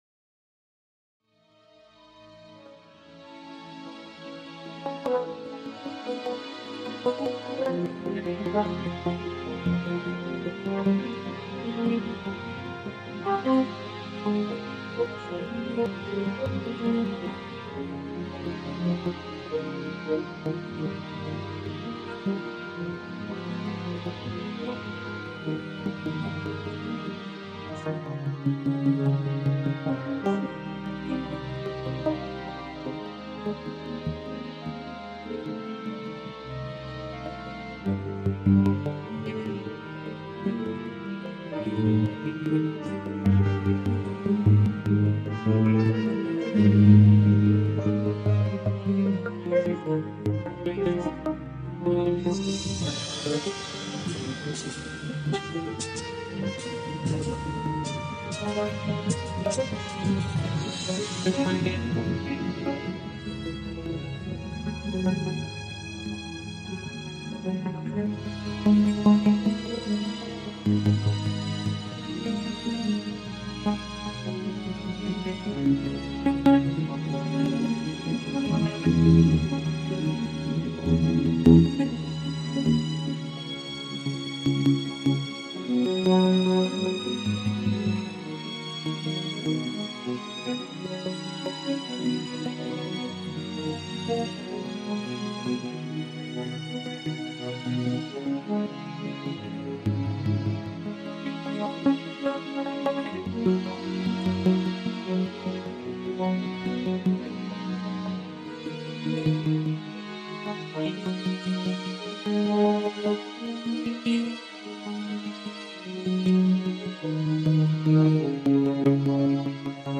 • BPM Variable: 60-110 (transiciones dinámicas)